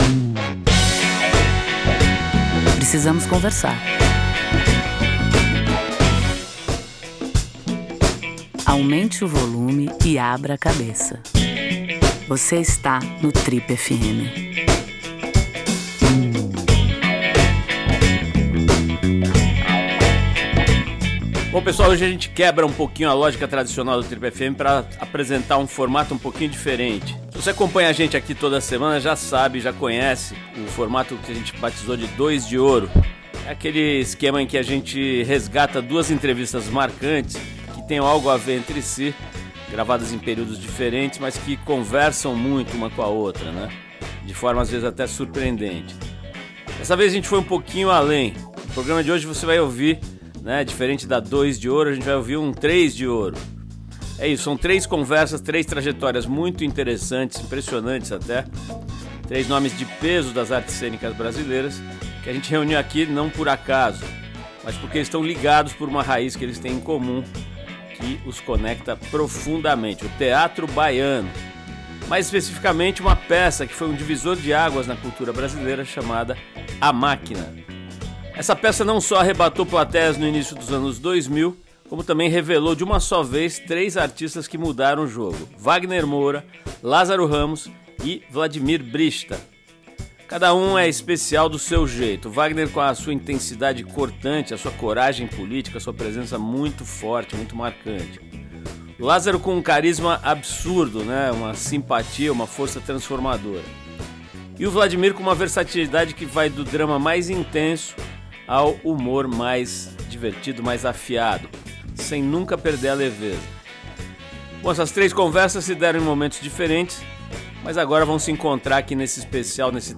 Em homenagem à turma de amigos mais carismática da TV, o Trip FM reúne trechos de conversas com os três atores numa edição especial